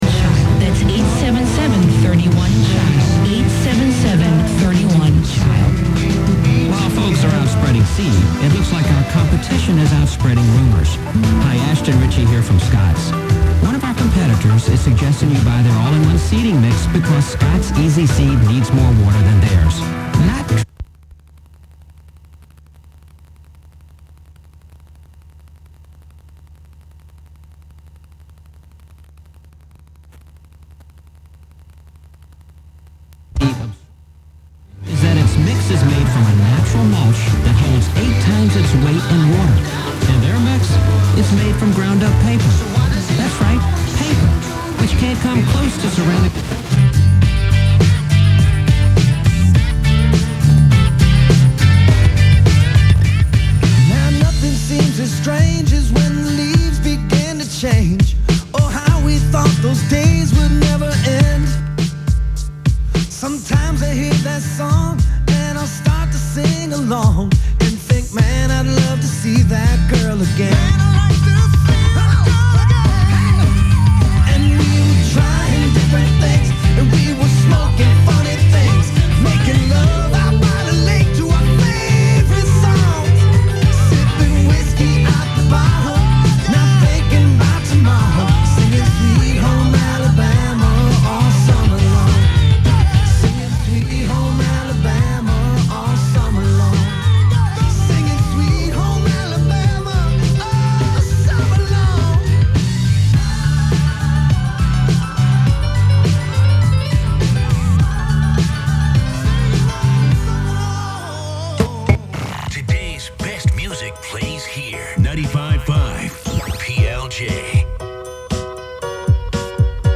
Transmission Arts & Experimental Sounds
A live weekly radio performance